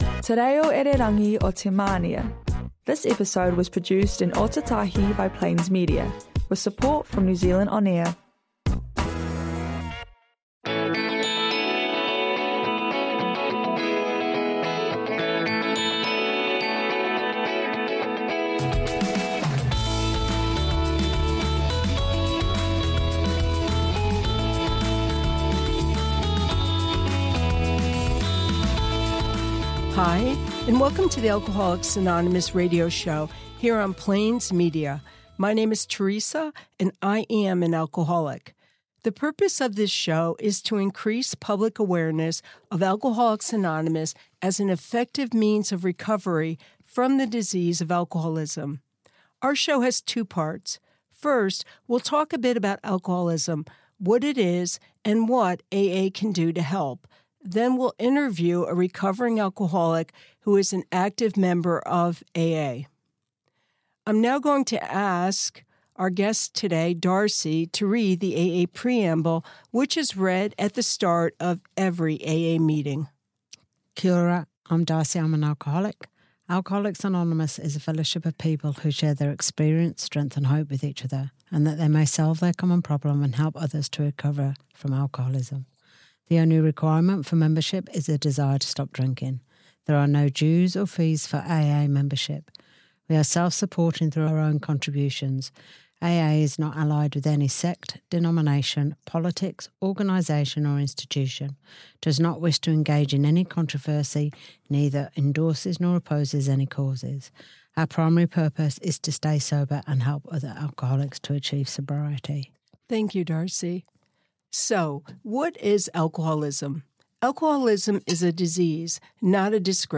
Alcoholics Anonymous Radio Show is a 25 minute broadcast, brought to you by members of Alcoholics Anonymous based in the Christchurch area. The purpose of this show is to increase public awareness of Alcoholics Anonymous as an effective means of recovery from the disease of alcoholism.
The format is simple - we talk briefly about what alcoholism is; then we interview one or two guest speakers, both of whom are recovering alcoholics, and we ask them to talk about their experiences with the disease and the one-day-at-a-time solution they have found in AA.